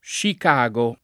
vai all'elenco alfabetico delle voci ingrandisci il carattere 100% rimpicciolisci il carattere stampa invia tramite posta elettronica codividi su Facebook Chicago [ingl. šik # a g ëu ; italianizz. + šik #g o , meglio che © ik #g o ] top.